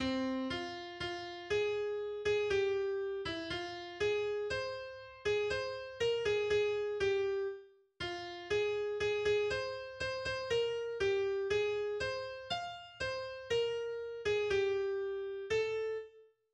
vaterländischer Gesang